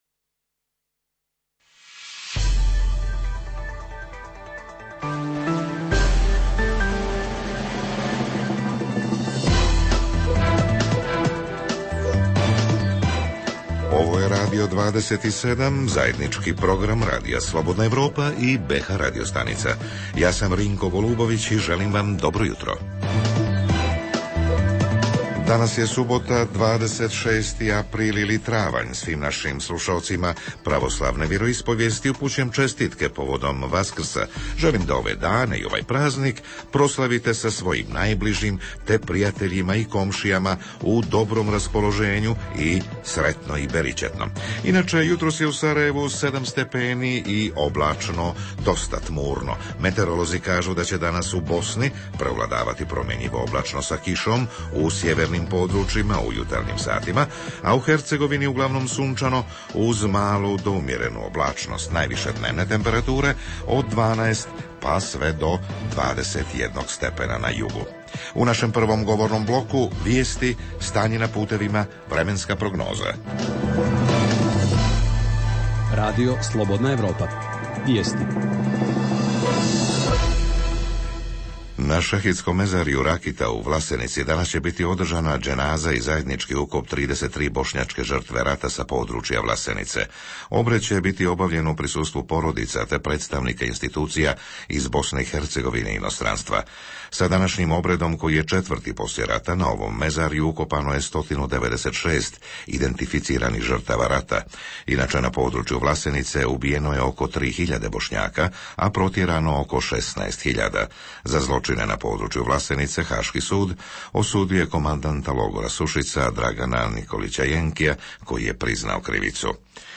Jutarnji program za BiH koji se emituje uživo. Govorimo o Vaskrsu, Uskrsu, religijskog toleranciji, o tome da li učimo našu djecu da poštuju i običaje drugih etničkih i religijskih skupina.
Redovni sadržaji jutarnjeg programa za BiH su i vijesti i muzika.